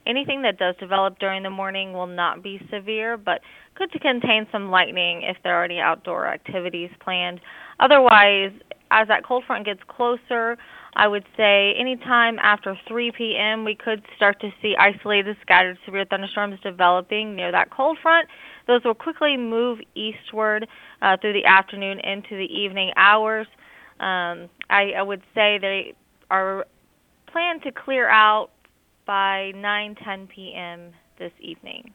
National Weather Service Meteorologist